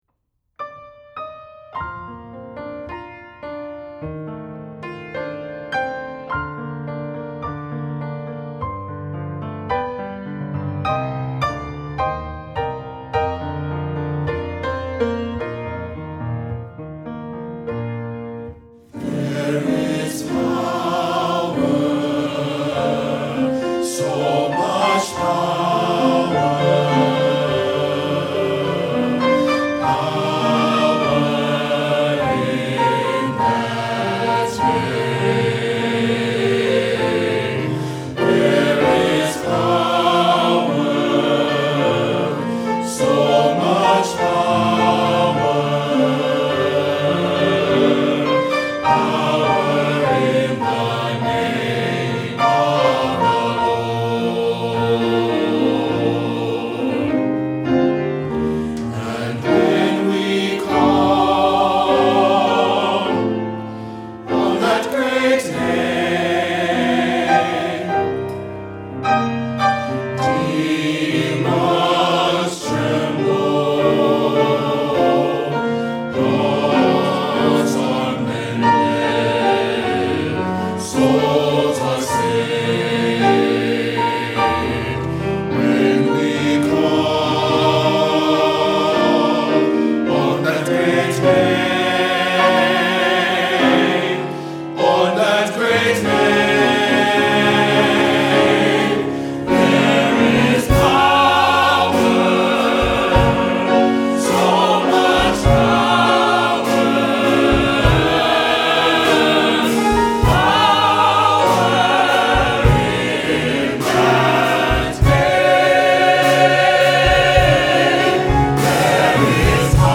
Voicing: SATB; Solo